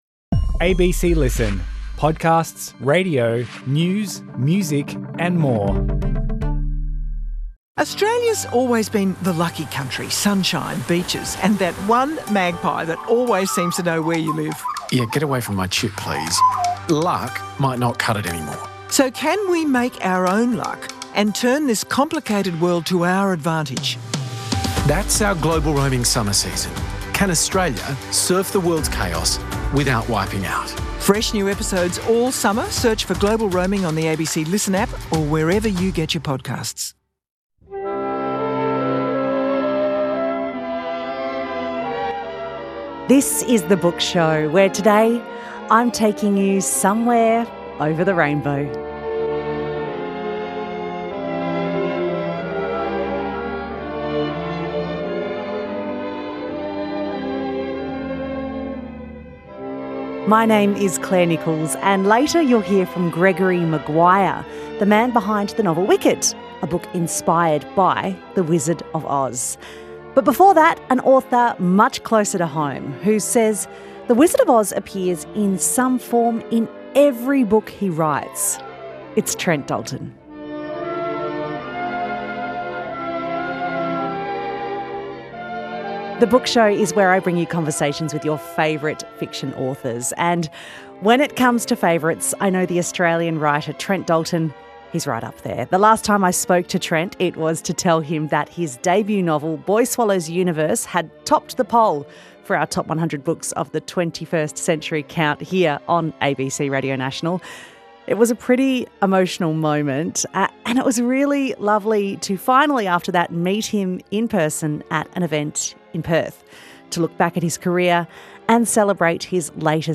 in front of a Perth crowd
This interview was first broadcast 14 April 2025, listen to the full interview here .